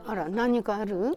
Aizu Dialect Database
Final intonation: Rising
Location: Aizumisatomachi/会津美里町
Sex: Female